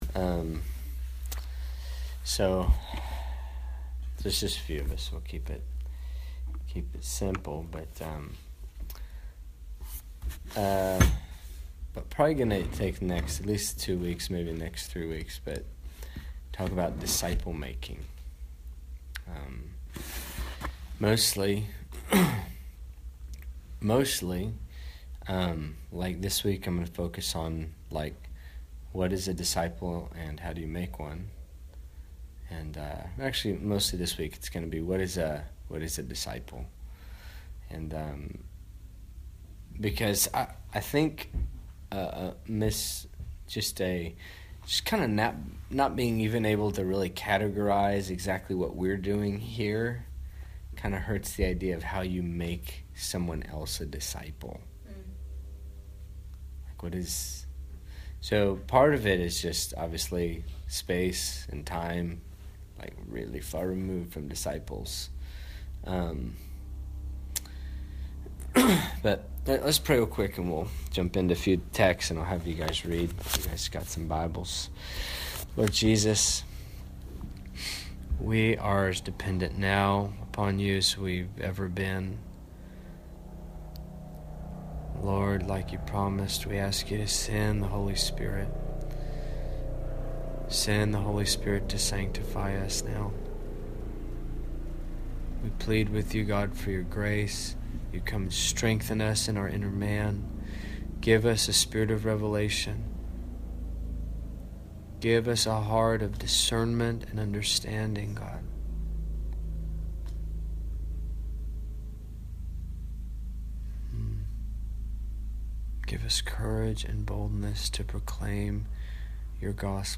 Here is the audio for last weeks time of teaching and exhortation. This time together has been titled, “Making Disciples Pt. 1” We pray it is a blessing.